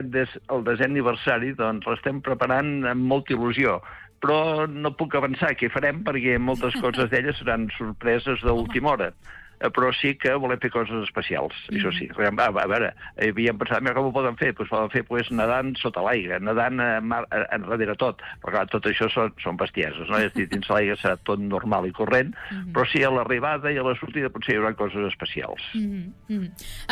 EntrevistesProgramesSupermatí